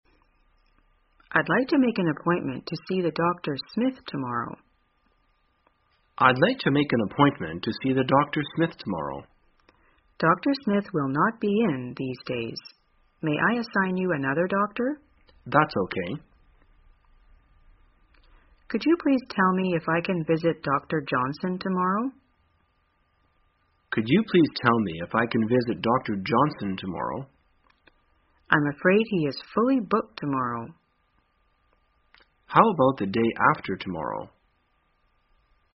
在线英语听力室生活口语天天说 第182期:怎样预约医生的听力文件下载,《生活口语天天说》栏目将日常生活中最常用到的口语句型进行收集和重点讲解。真人发音配字幕帮助英语爱好者们练习听力并进行口语跟读。